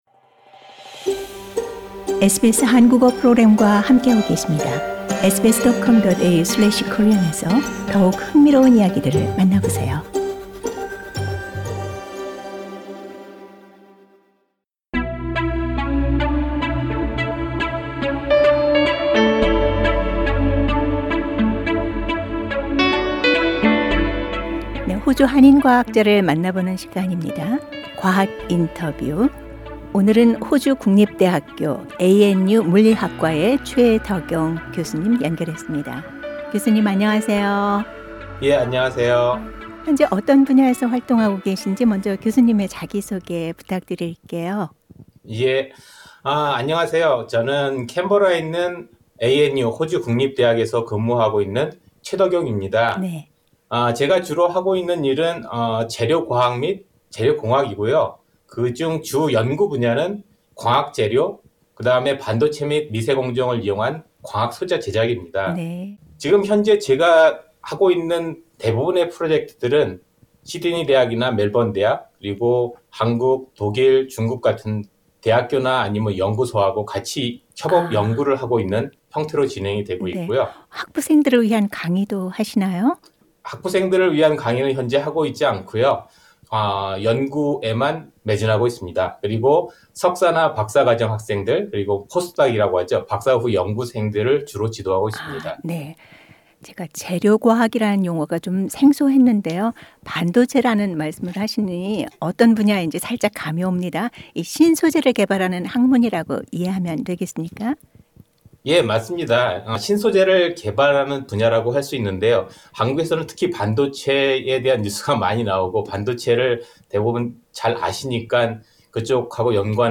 [과학人터뷰]